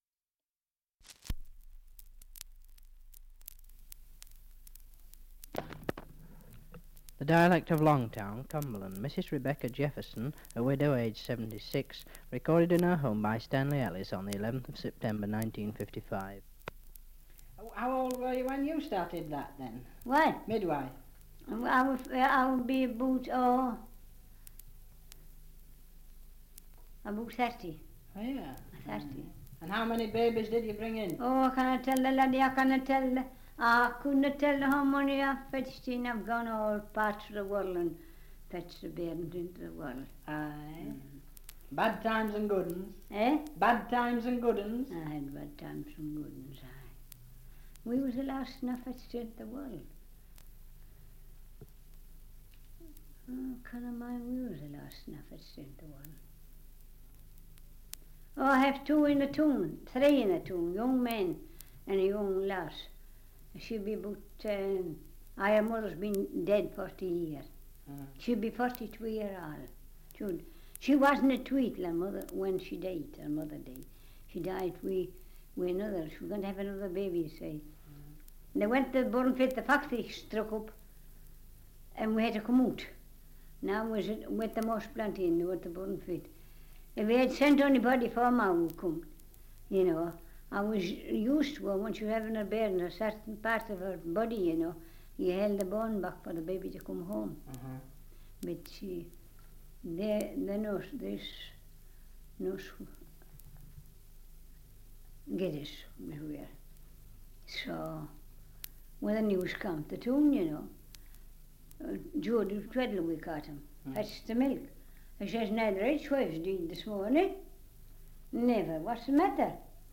Survey of English Dialects recording in Longtown, Cumberland
78 r.p.m., cellulose nitrate on aluminium